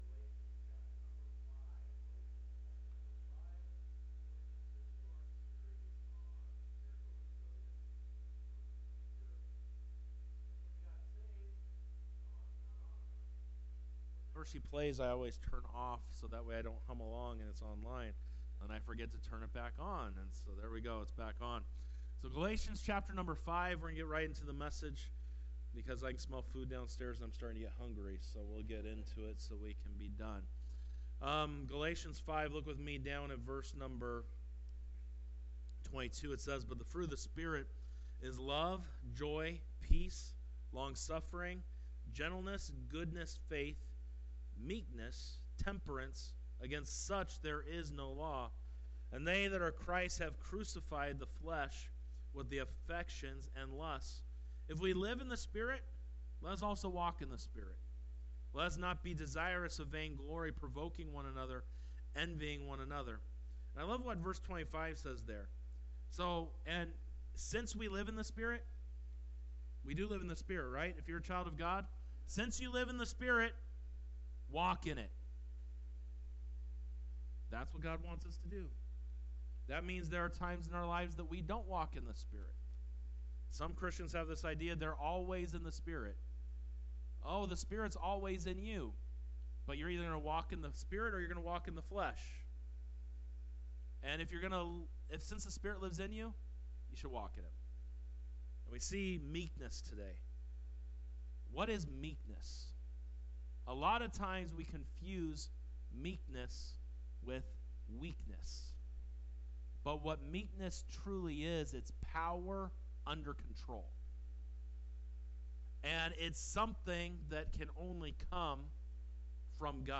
Sunday Evening Service 01:26:25 - The Fruit of Meekness